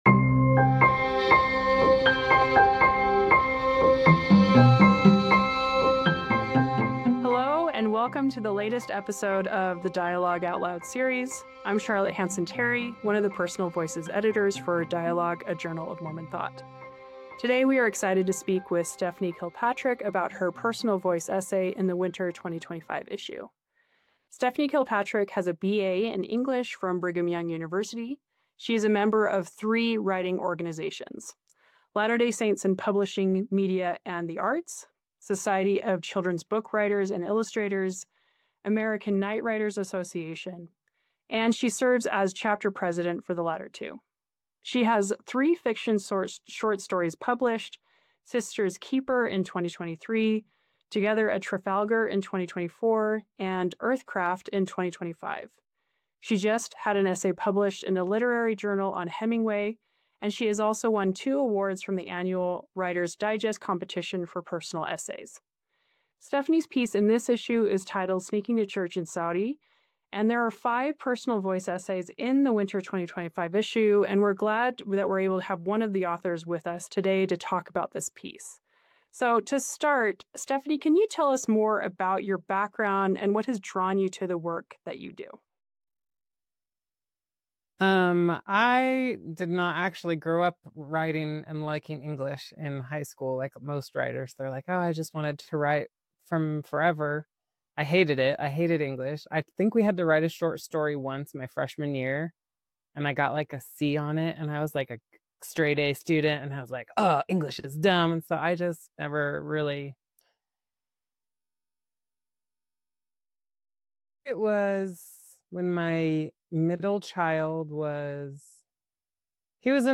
Faith Under Constraint: Sneaking to Church in Saudi Arabia: A Conversation